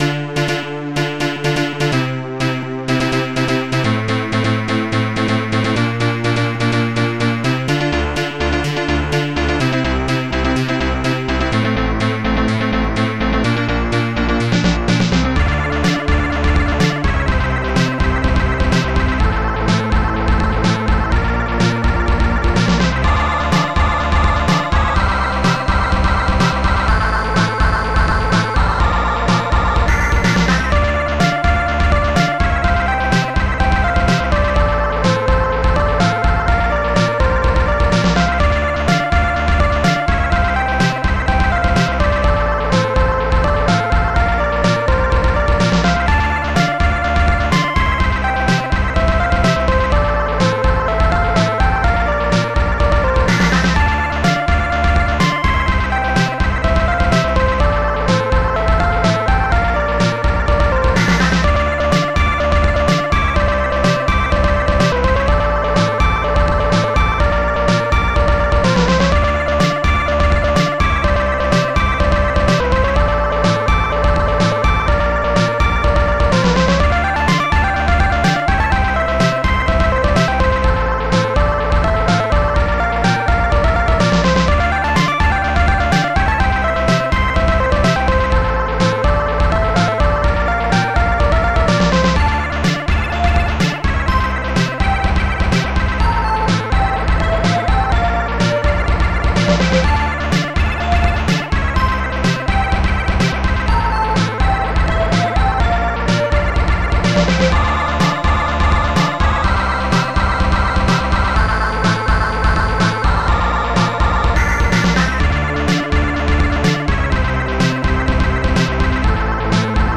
st-02:accgit
st-02:hammerbass
st-01:strings4
st-02:snare6
st-01:bassdrum3
st-01:epiano
st-02:acopiano
st-02:ahhvox